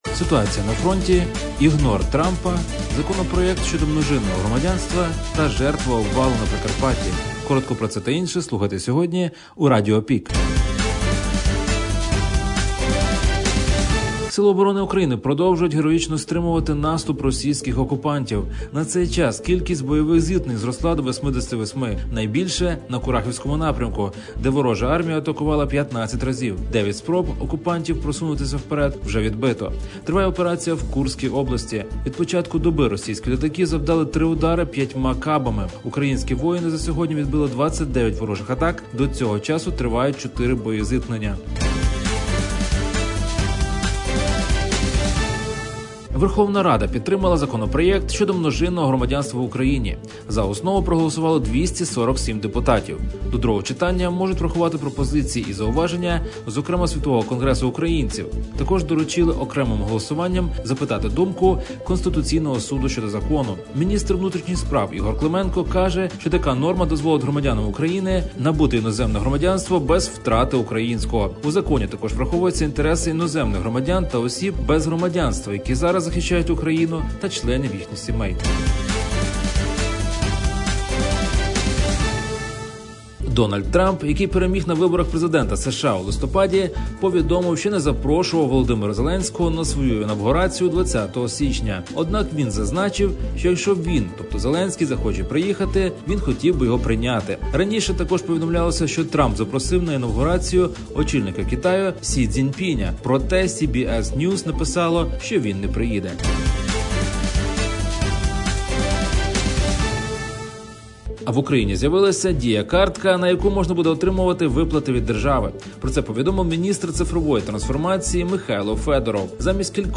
Пропонуємо актуальне за день у радіоформаті.